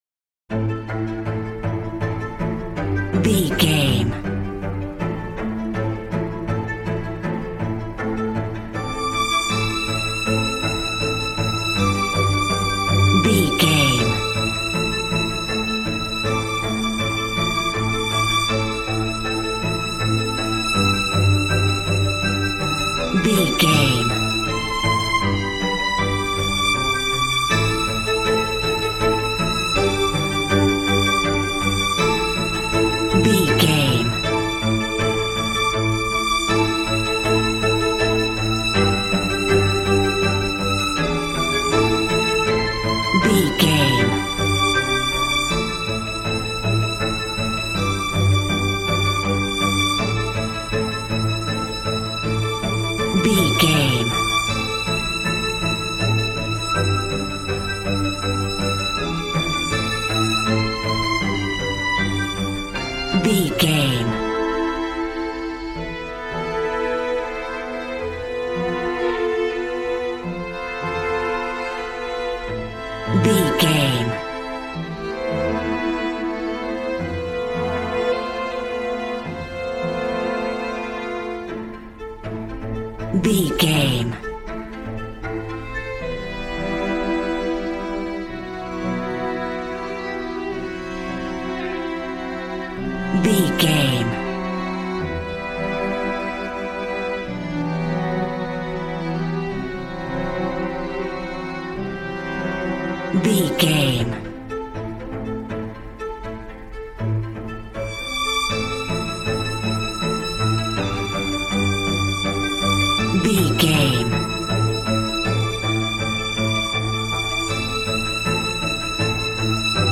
Modern film strings for romantic love themes.
Regal and romantic, a classy piece of classical music.
Aeolian/Minor
cello
violin
brass